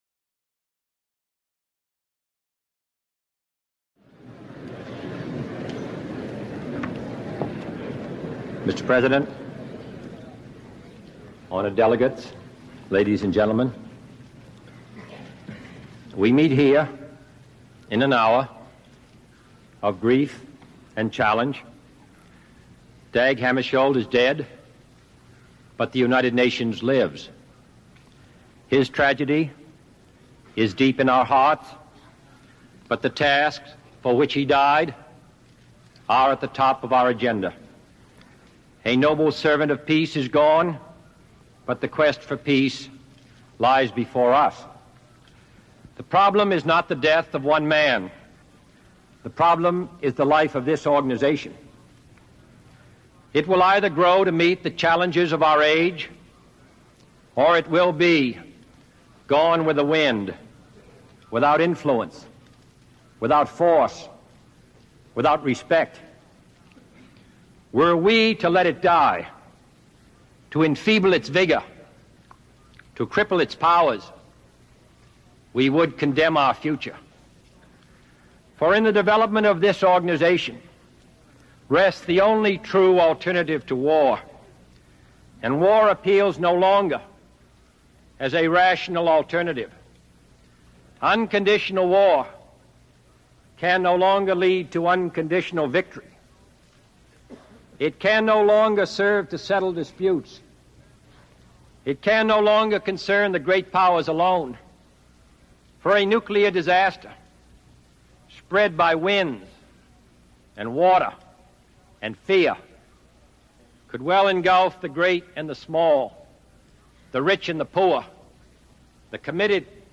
President John F. Kennedy United Nations General Assembly Address